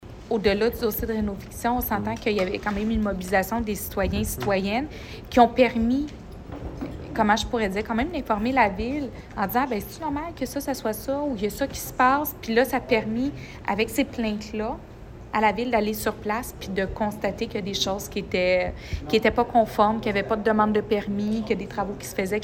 La mairesse Julie Bourdon.